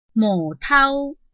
拼音查詢：【南四縣腔】tau ~請點選不同聲調拼音聽聽看!(例字漢字部分屬參考性質)